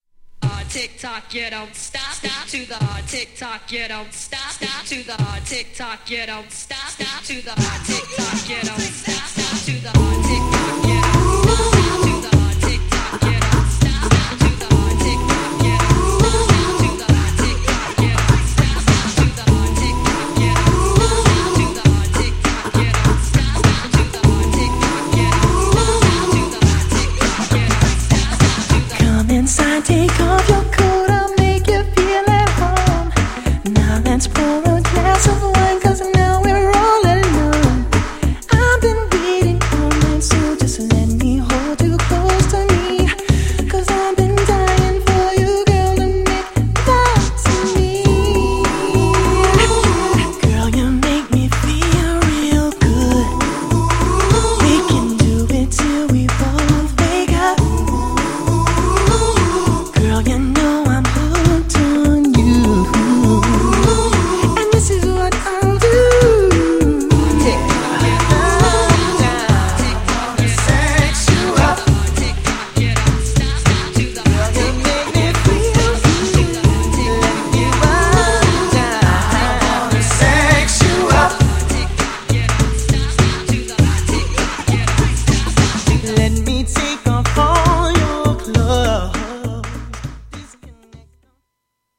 R&Bのアーティストが勢揃いで
GENRE Hip Hop
BPM 96〜100BPM